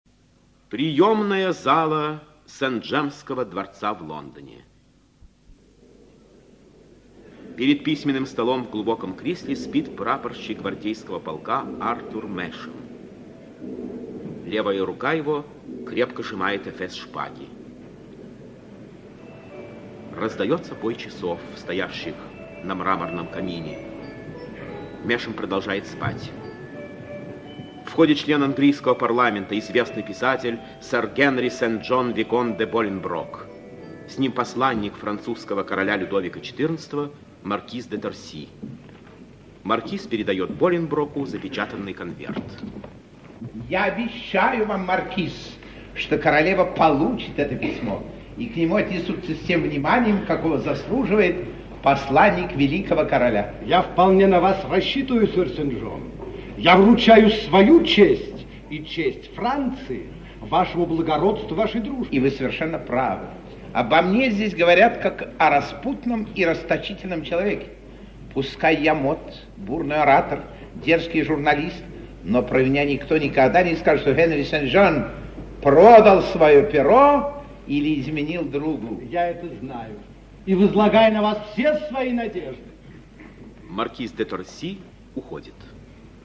Например, при трансляции спектакля "Стакан воды" (по Эжену Скрибу) Малого Академического театра СССР в 1954 году звукорежиссёры радио сделали "микс" между записанными в театре звуками и дополнительными студийными звуками, создав тем самым новый вариант аудиопартитуры данного спектакля:
Трансляция: звуки шагов.
Трансляция: диалог (речь и сценические шумы).